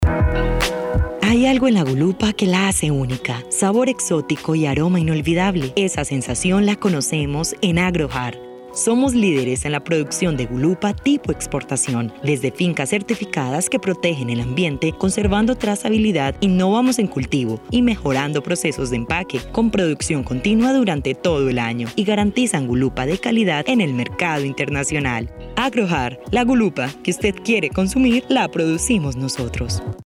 A soft voice with the kindness of a friend and the strength of a professional, from Colombia.
Sprechprobe: Werbung (Muttersprache):
I love speak with a blend between a soft and strong voice. Fresh, calm, sweet and deep when is needed.
Locución comercial_Agrojar.mp3